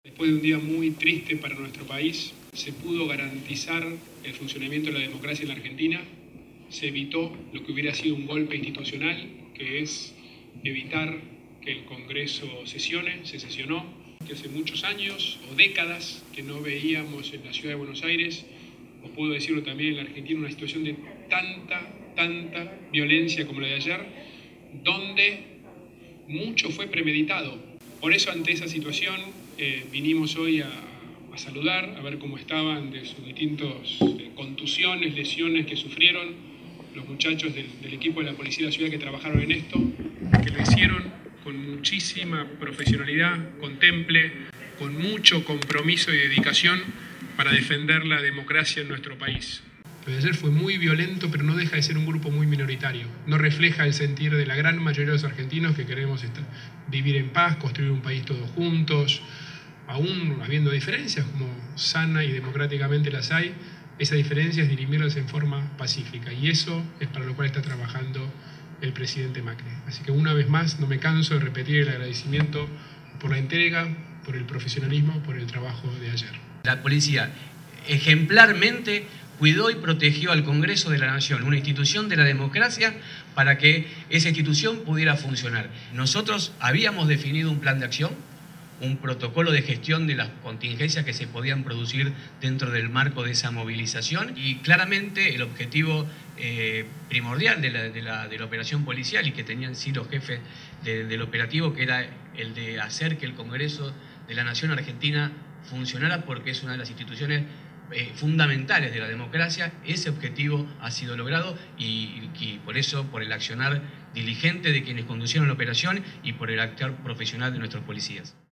El Jefe de Gobierno porteño visitó a oficiales que participaron ayer en los operativos de seguridad en la plaza del Congreso.